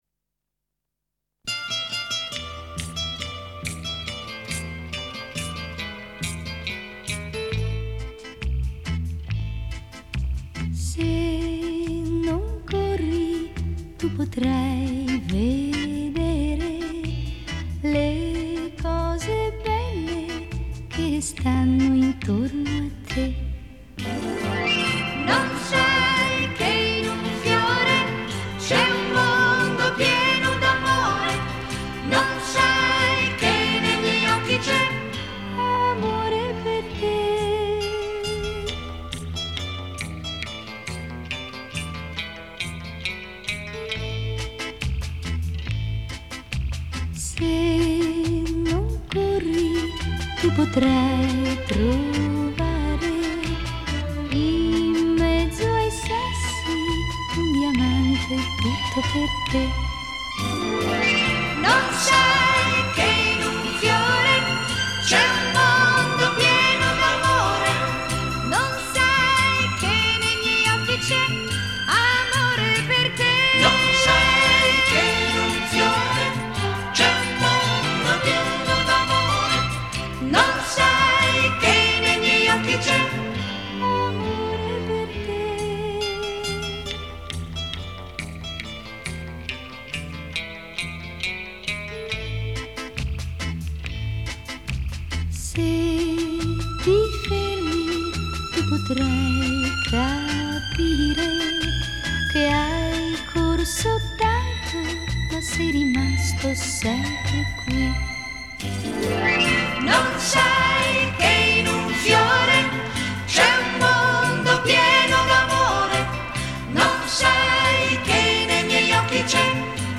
Genre: Retro Pop